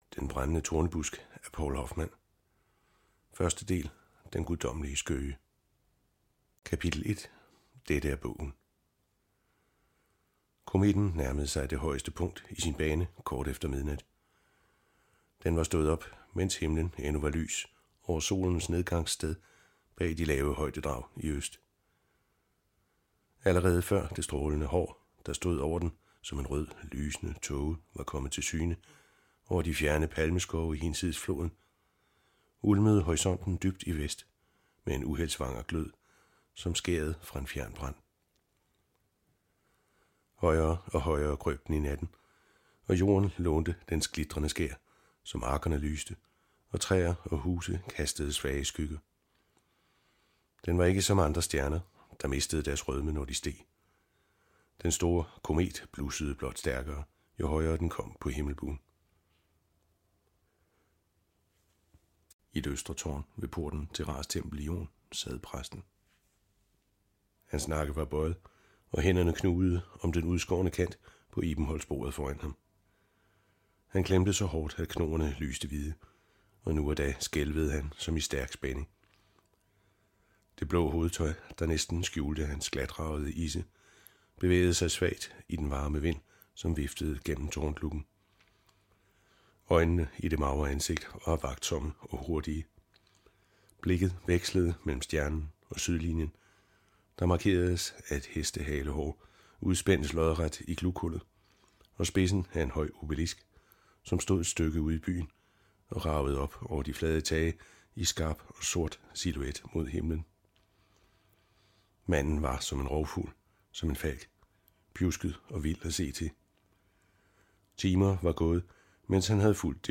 Hør et uddrag af Den brændende tornebusk Den brændende tornebusk Moses I Format MP3 Forfatter Poul Hoffmann Lydbog E-bog 149,95 kr.